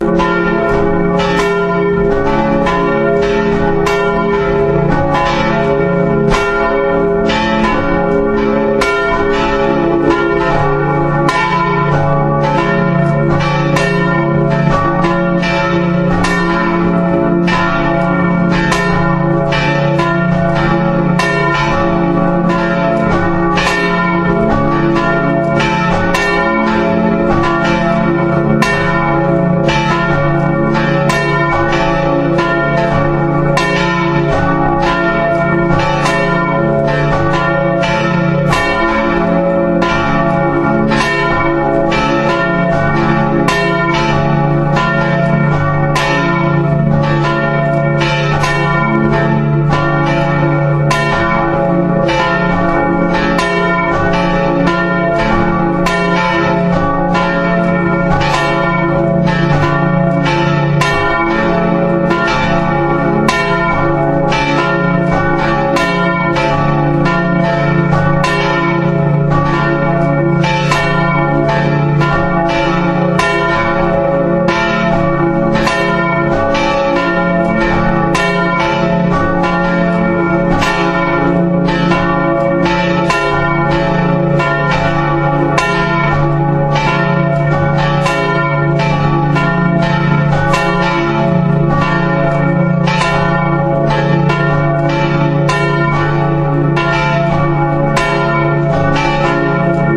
Geläut von St. Marien
Vielleicht hat sich so mancher bereits gewundert, warum die Glocken zu dieser Zeit in den Kirchen gemeinsam läuten.